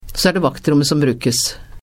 Legg merke til hvordan dette sies .